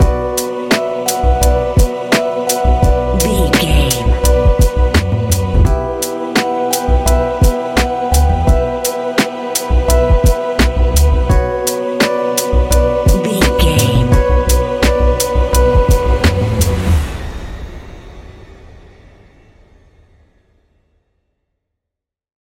Ionian/Major
B♭
laid back
relaxed
Lounge
sparse
new age
chilled electronica
ambient
atmospheric
morphing